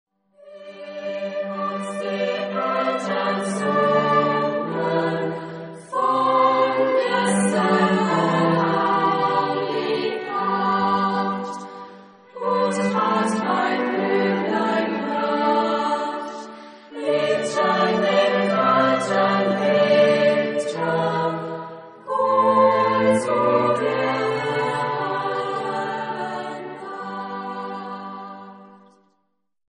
Genre-Style-Form: Sacred ; Christmas carol
Type of Choir: SSAA  (4 children OR women voices )
Tonality: G major